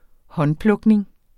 håndplukning substantiv, fælleskøn Bøjning -en Udtale [ -ˌplgˌneŋ ] Betydninger 1. det at plukke afgrøder i hånden (og ikke med maskine) omkring en tredjedel af æblerne går til most.